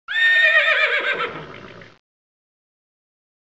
horse-neighing-sound-effect-mp3cut.mp3